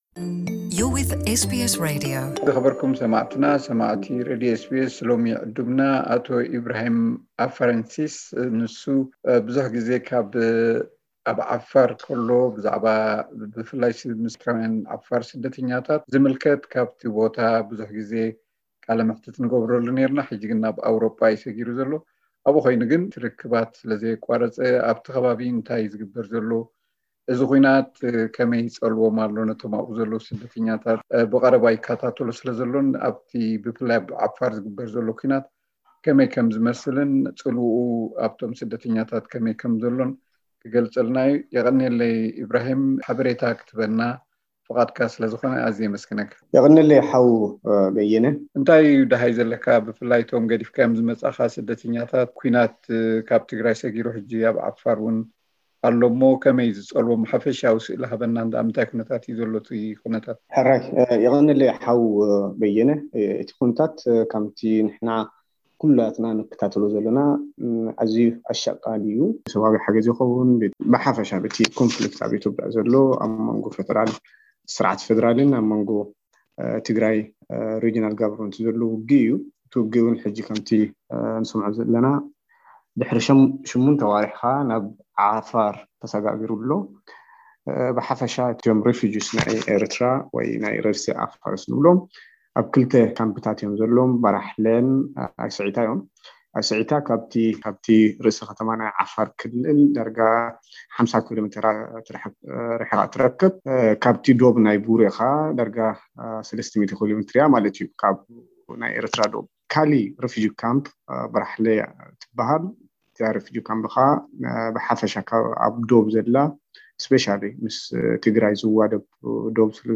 ቃለ መሕትት